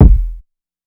CDK Night Kick.wav